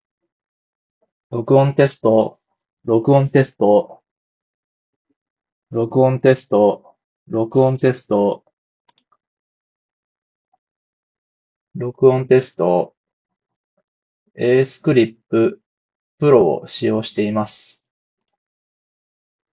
実際にマイク性能をチェックしてみると、予想以上に収音品質は良好という印象です。
イヤーカフ型・オープンイヤーという構造上、マイク性能にはあまり期待していませんでしたが、装着者の発言内容はしっかりと明瞭に拾い上げられており、声の輪郭もはっきり伝わっていました。
▼ACEFAST ACECLIP Proで録音した音声
収録時には部屋のエアコンをフル稼働させていましたが、空調音や環境ノイズは効果的に抑制されており、実際の音声では話し声だけが自然に強調されて聞こえます。